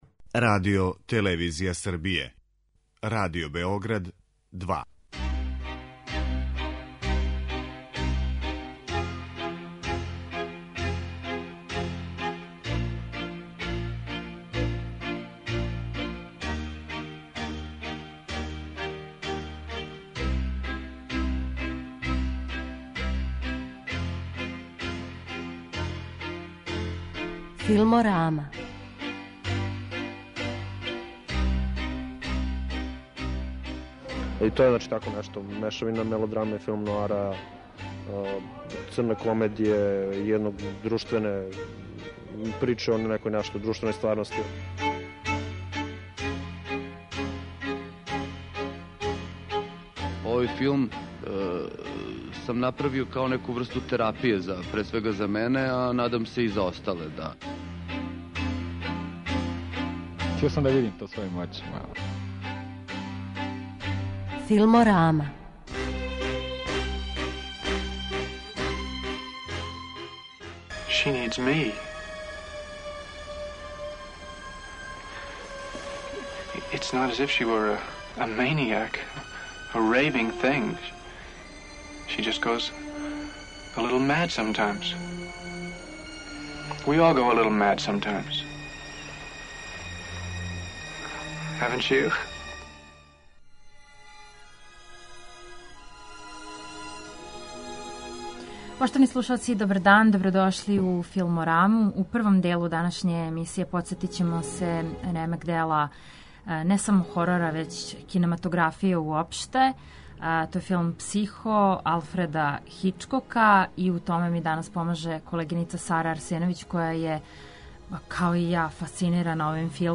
Емисија о седмој уметности
чућемо новинаре, критичаре и љубитеље овог филма и Хичкока